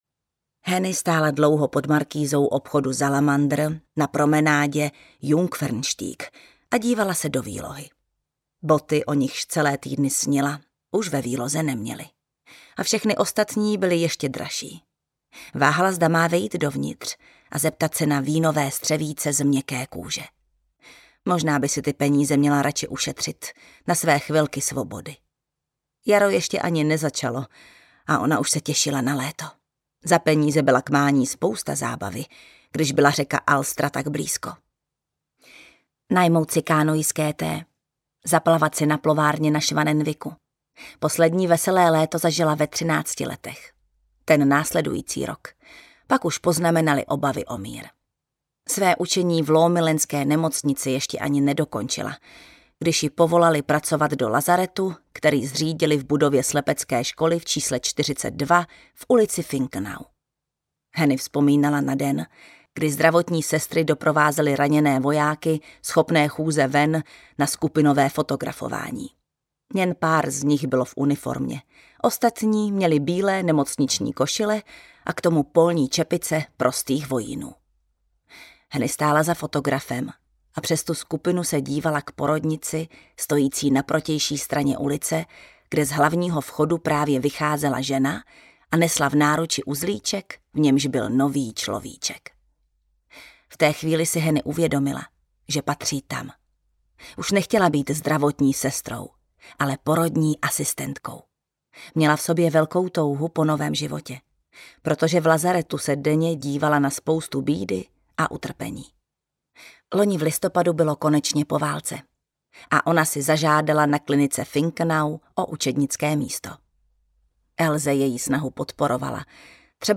Dcery nové doby I. audiokniha
Ukázka z knihy
• InterpretJana Stryková